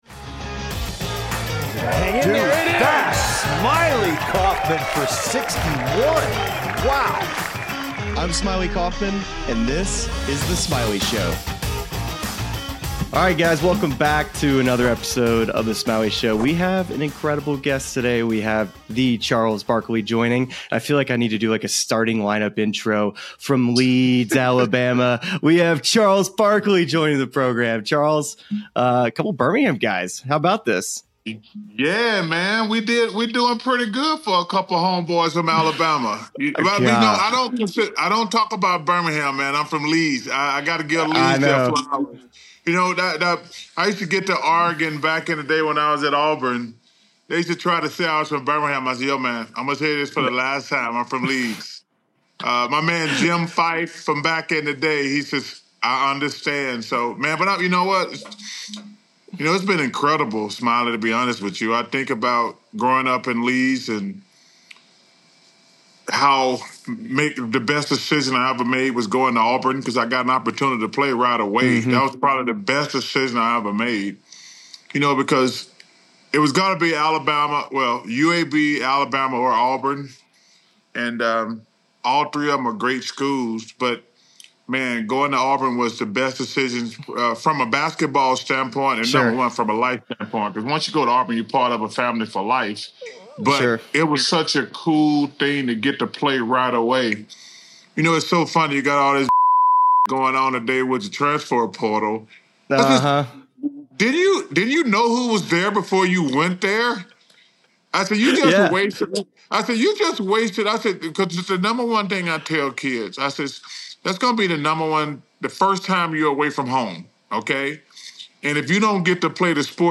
This week on The Smylie Show, Smylie Kaufman sits down with NBA & broadcasting legend Charles Barkley for a wide-ranging conversation covering everything from the future of Inside the NBA to the environment he'd create at Bethpage if he was USA's captain.